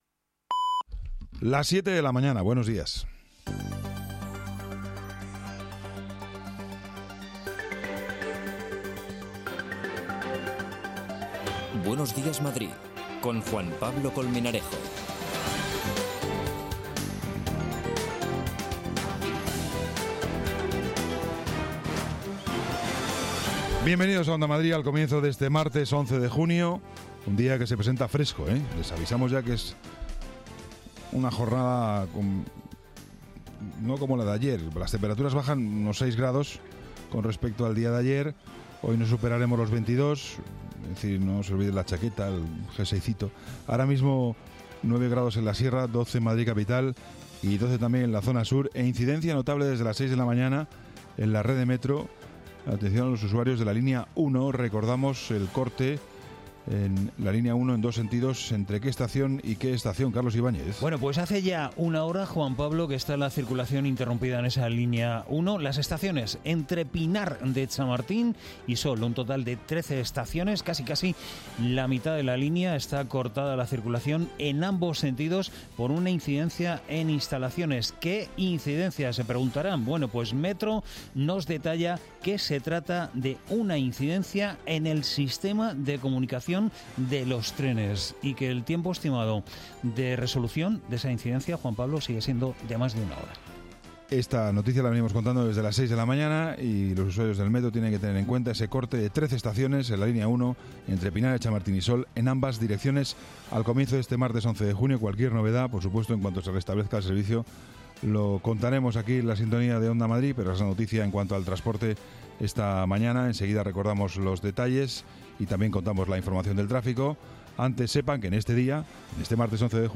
La unidad móvil de Onda Madrid se desplaza a Chamberí, en concreto a la calle Ponzano. Los vecinos están hartos del ruido de 72 bares en tan solo un kilómetro.